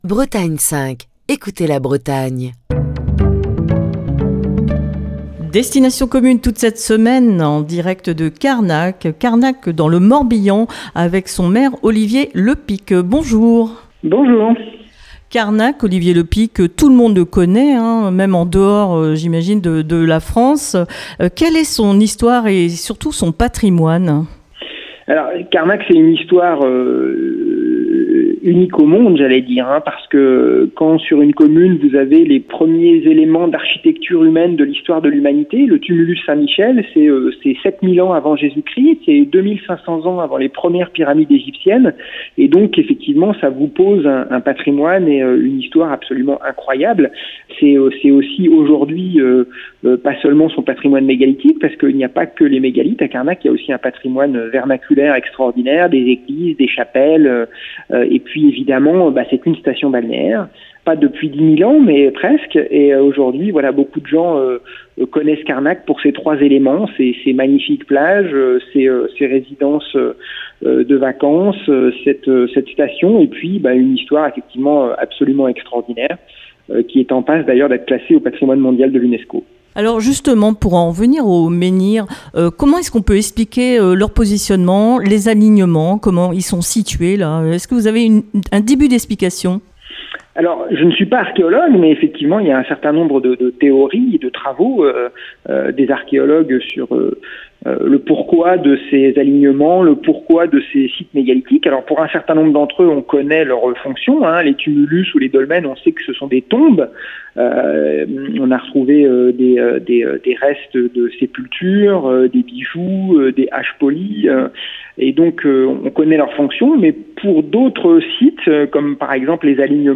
Cette semaine, Destination commune pose ses micros à Carnac, dans le Morbihan.